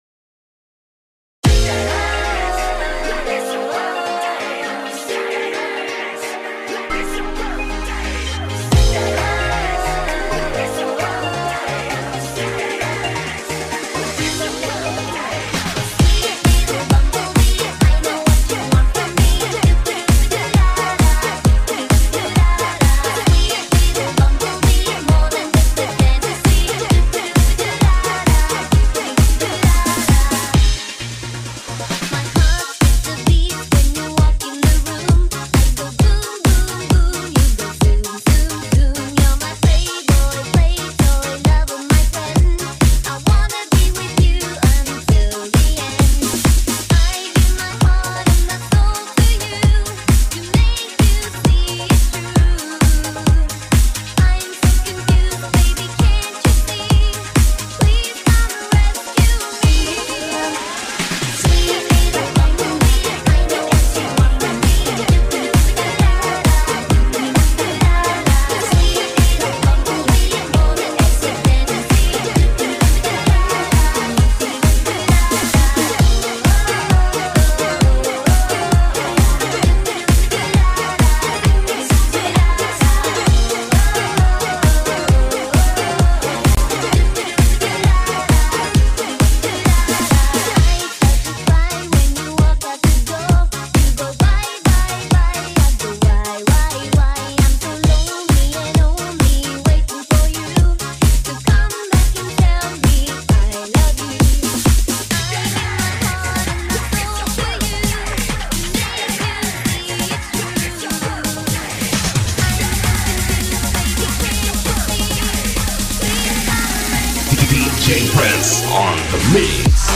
𝑴𝒖𝒔𝒊𝒄 𝑹𝒆𝒎𝒊𝒙